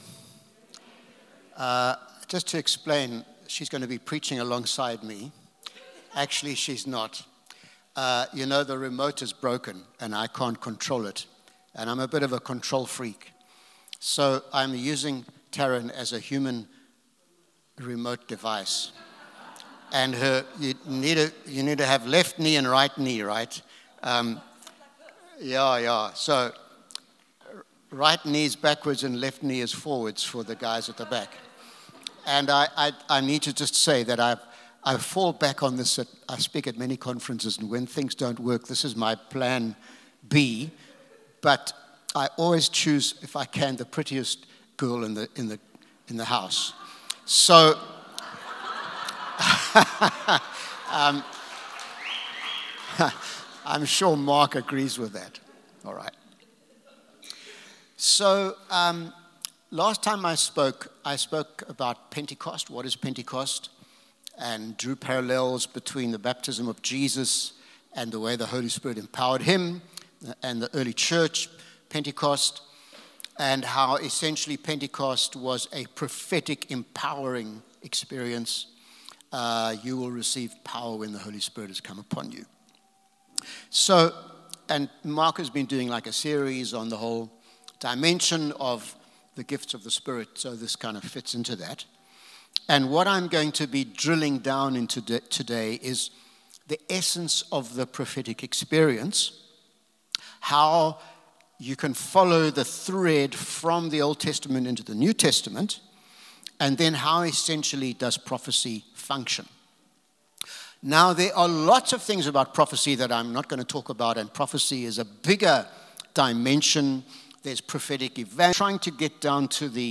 Sunday Service – 5 January
Sermons